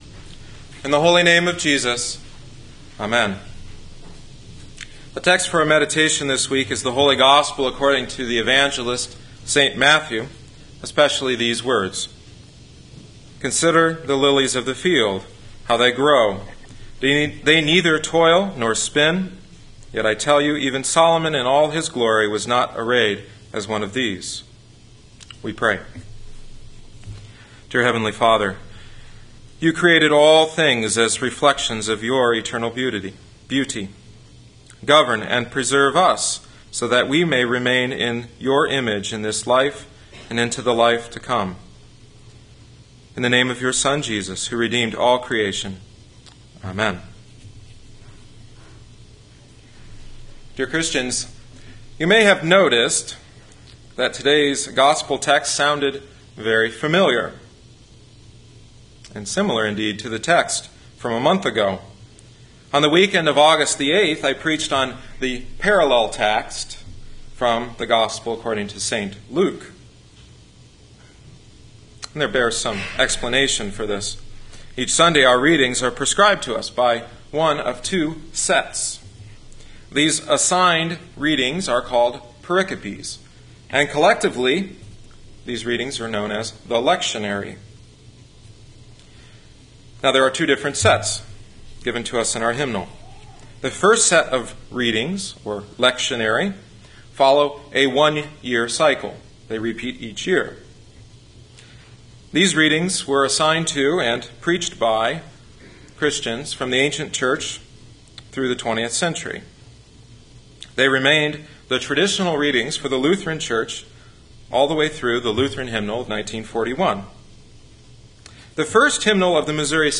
Sept 2010 Fifteenth Sunday after Trinity Matthew 6:24-34 “Consider the Lilies”